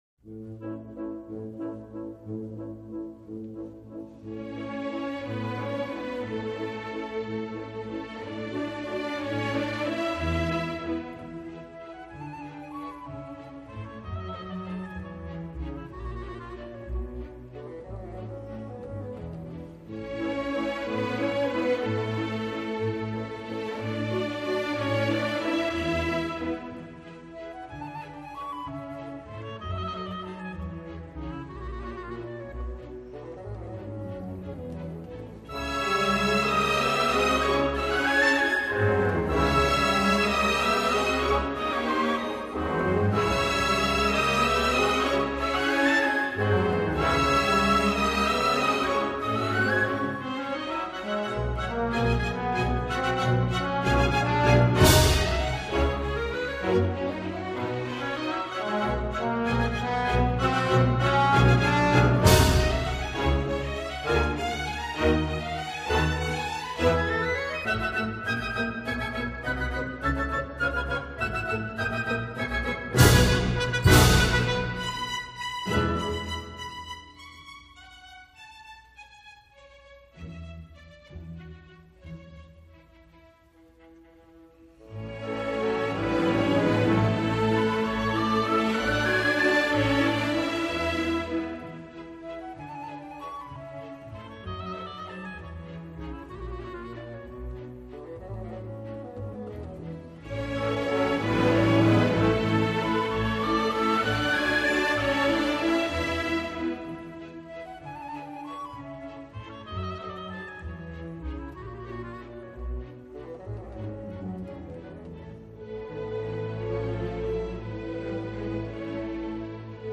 Track 06 צייקובסקי - אגם הברבורים - ואלס הברבורים.MP3